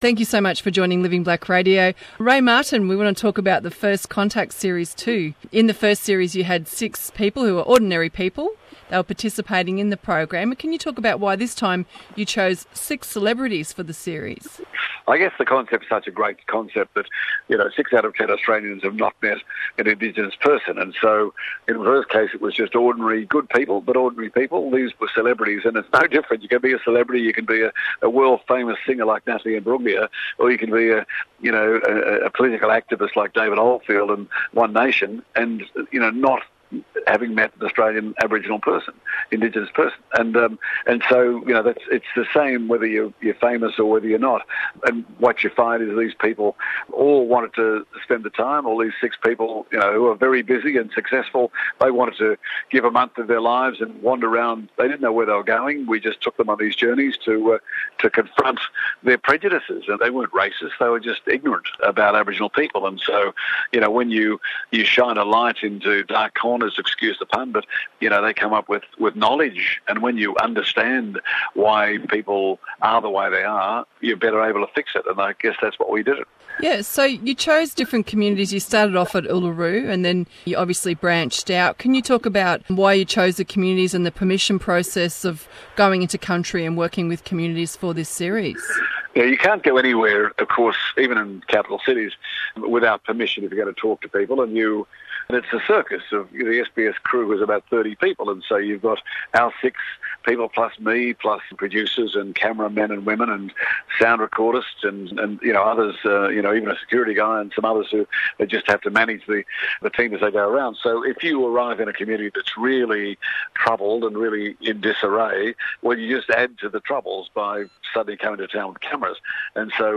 Listen to the Podcast of the full interview with Ray Martin on Living Black Radio.